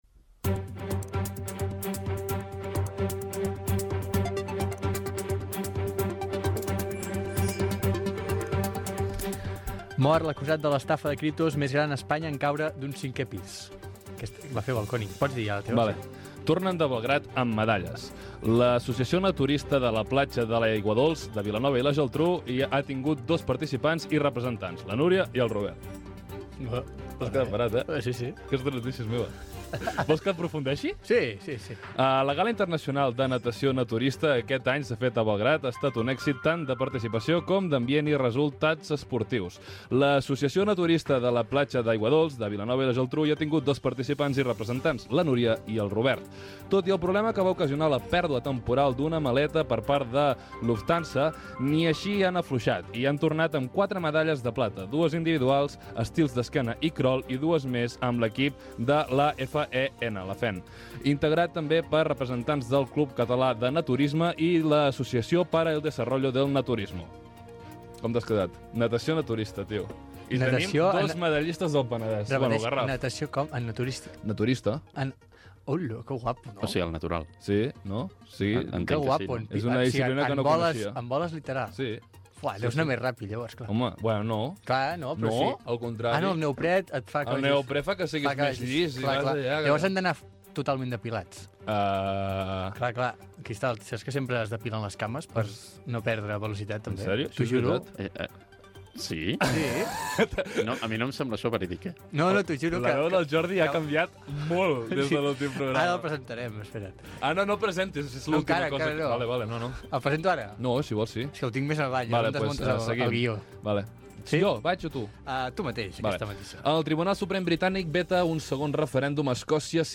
Resum informatiu de fets curiosos, equip del programa i diàleg entre els presentadors sobre les seves feines
Entreteniment